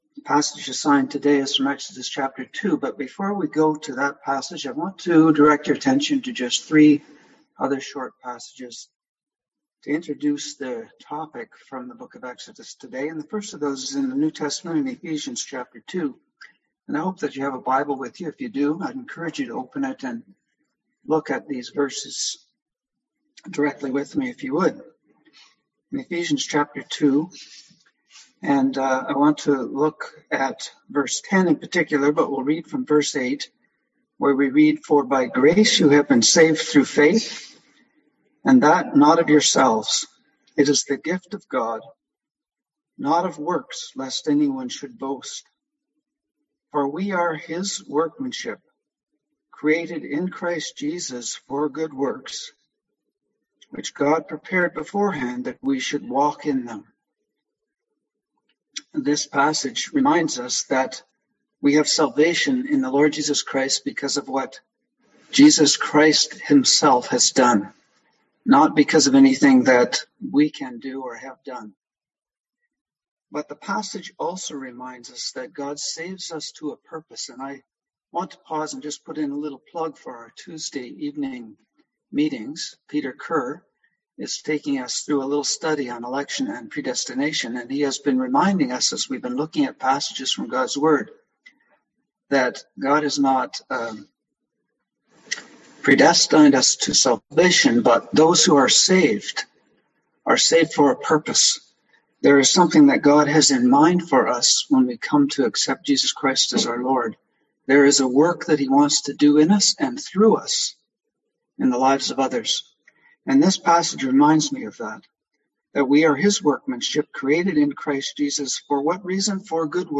Passage: Exodus 2:11-4:23 Service Type: Sunday AM